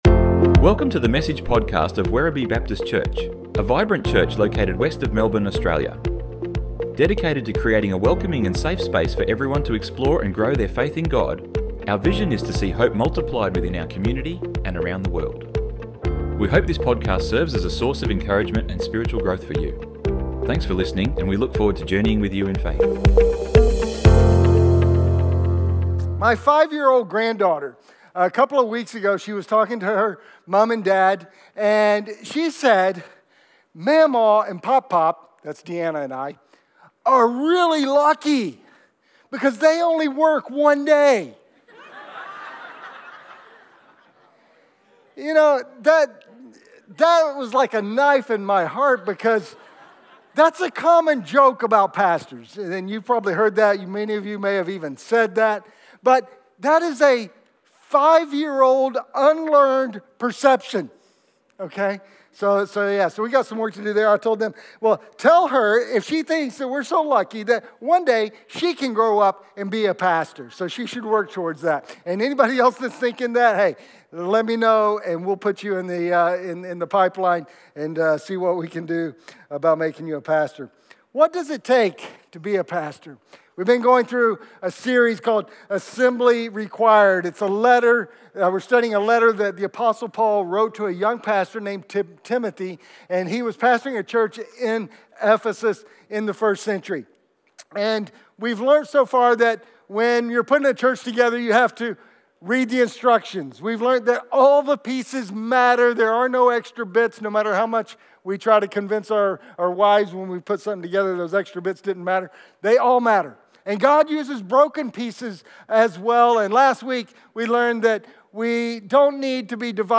Messages | Catalyst Baptist Church